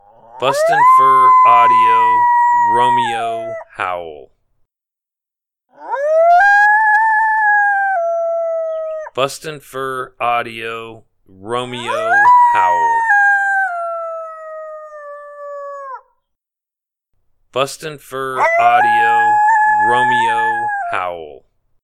Romeo Howl is our oldest male Coyote Apollo, in this series of Lone Howls.
Apollo is alone and lonely. Excellent stand starter howl.
BFA Romeo Howl Sample.mp3